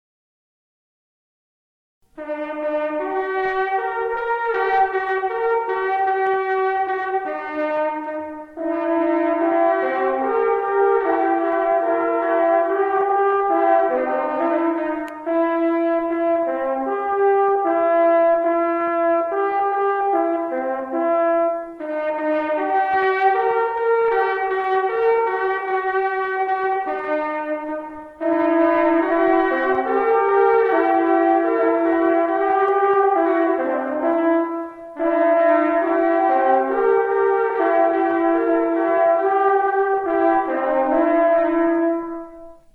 A set is made up of two daudytes, which are tuned in unison and at intervals of a second or fourth.
sutartinė